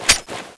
y23s1sfsmg_draw.wav